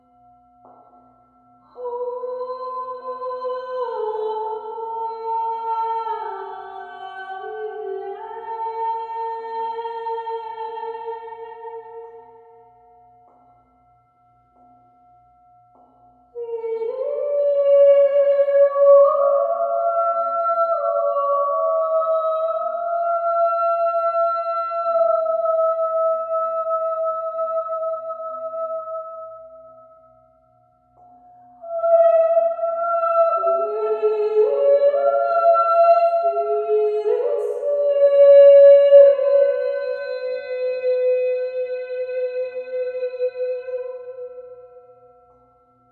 Bol Chantant et Voix                    Durée 27:39